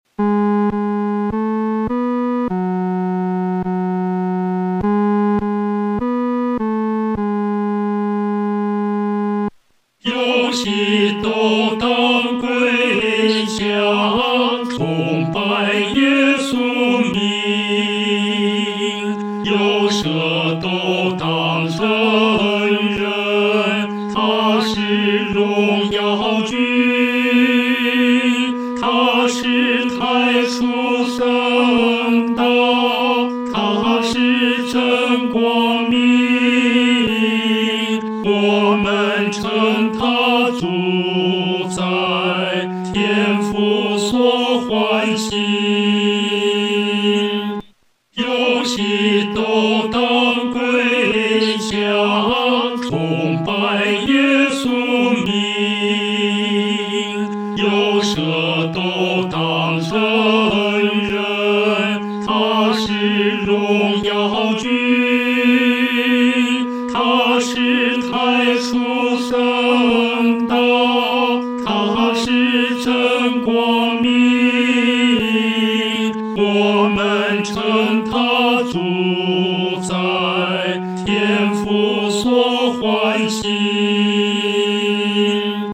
男高
这首诗歌宜用中庸的速度来弹唱。